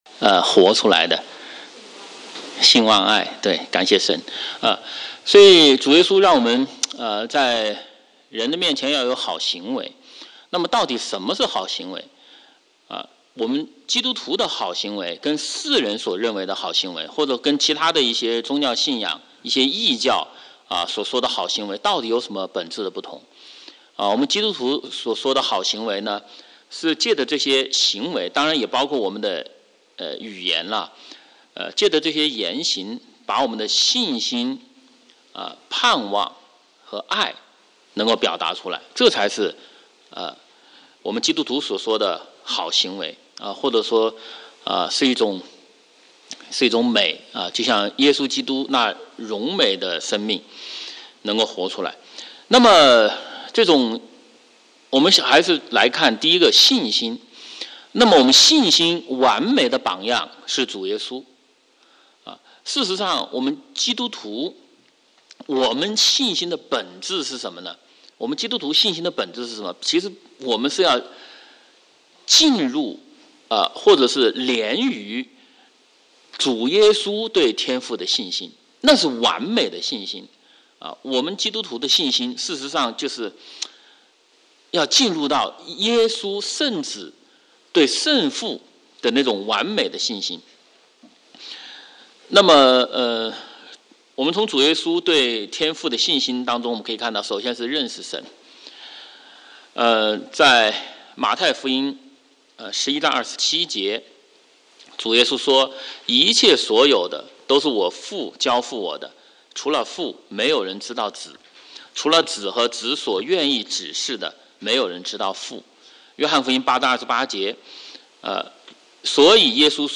Adult Sunday school:Sundays @ 9:30am